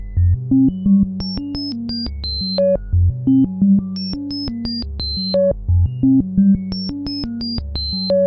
模块化合成器 " BFC样品scifi
描述：用合成振荡器制作的旋律循环
Tag: SoundEffect中 实验 电子 怪异 实验室 数字 未来派 声音设计 未来 奇怪的 抽象的 奇特的 科幻